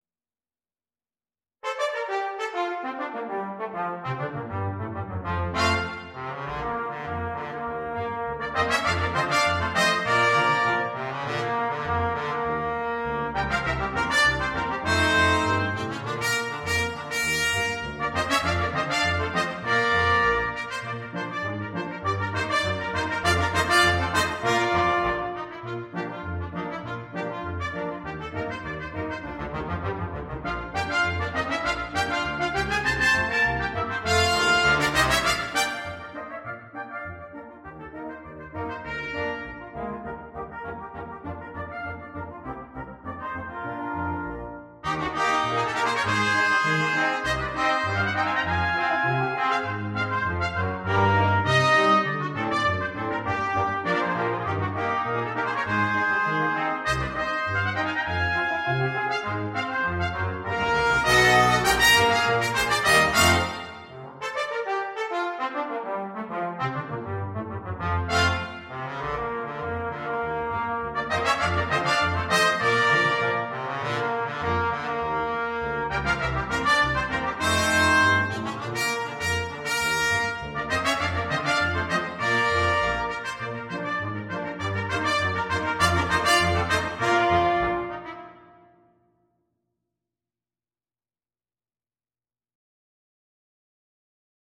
для брасс-квинтета